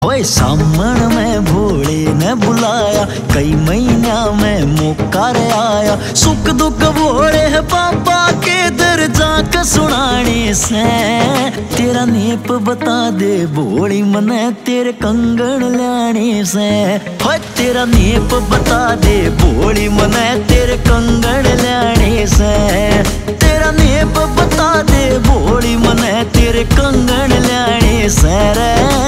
Haryanvi Songs
• Simple and Lofi sound
• Crisp and clear sound